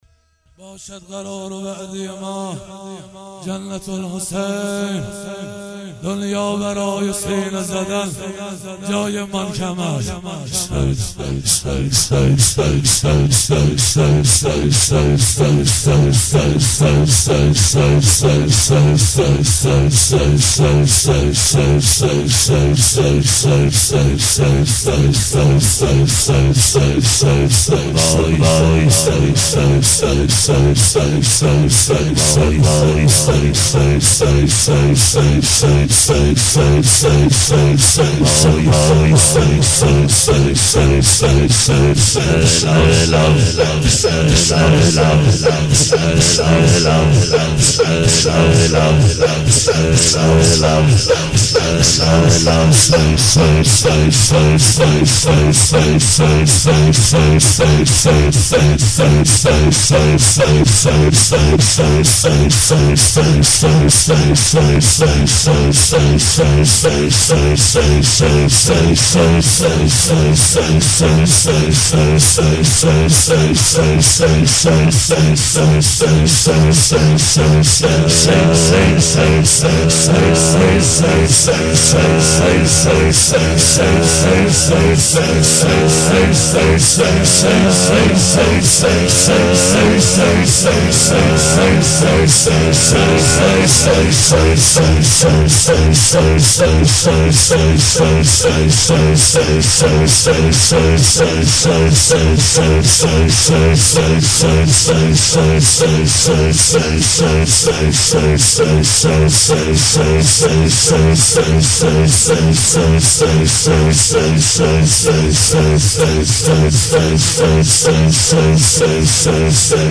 هیئت شیفتگان جوادالائمه علیه السلام مشهد الرضا
شهادت امام صادق۱۳۹۸